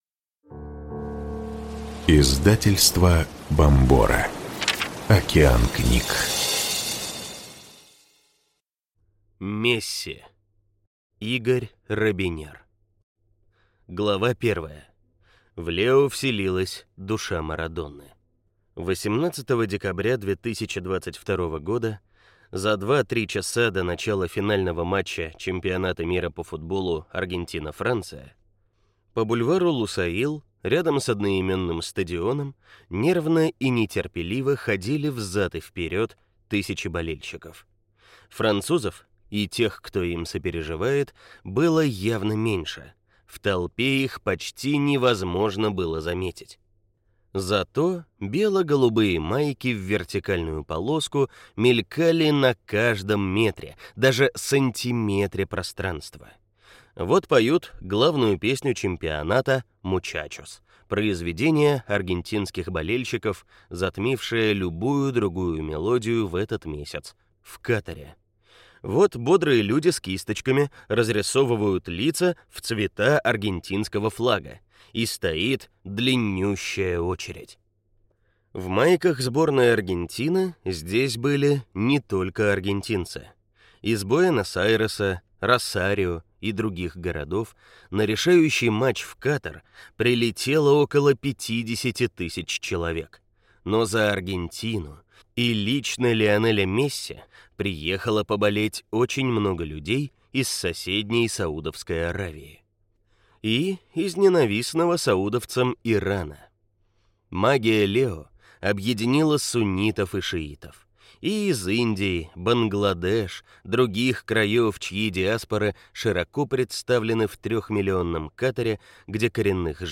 Аудиокнига Месси | Библиотека аудиокниг